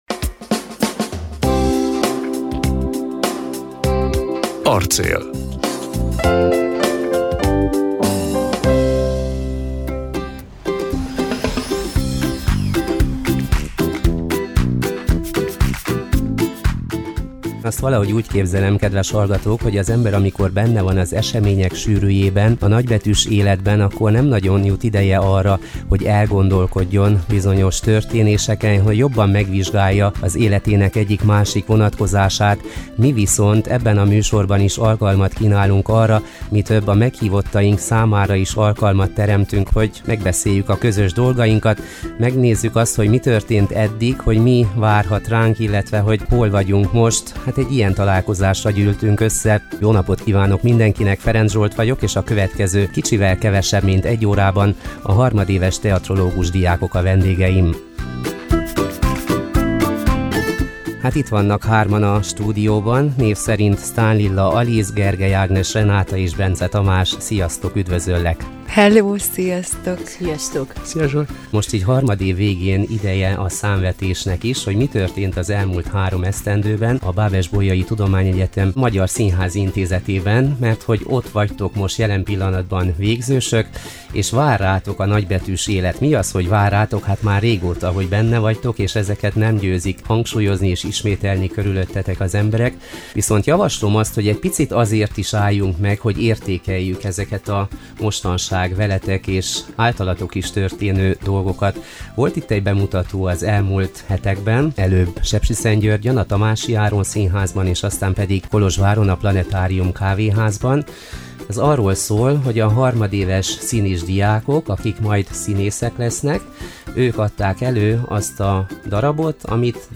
Az elmúlt három egyetemi évükről és a színházhoz való viszonyulásukról is beszélgettünk a BBTE Magyar Színházi Intézetének végzős teatrológus hallgatóival.